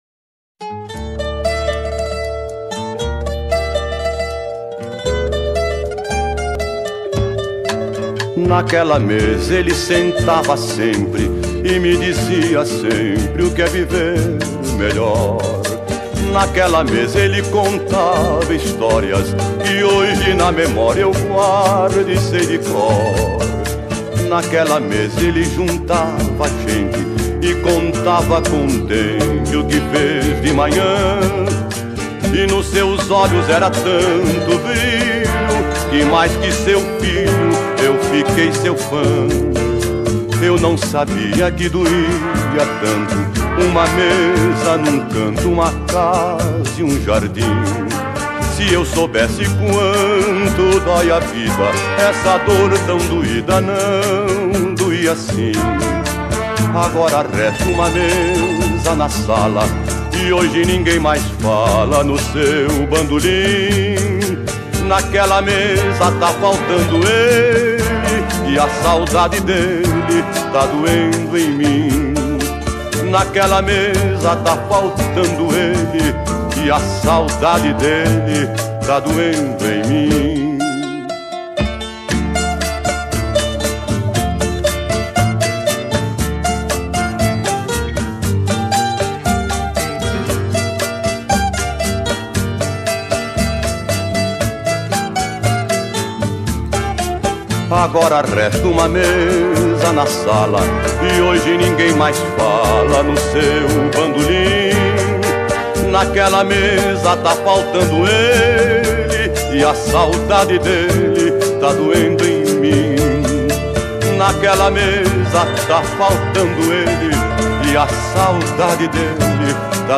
2025-02-23 00:52:56 Gênero: MPB Views